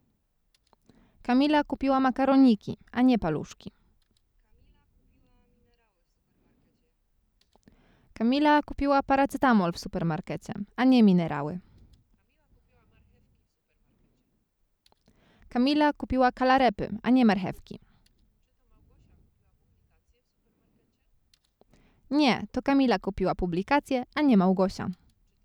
Female: Answering
Kobieta: Odpowiadanie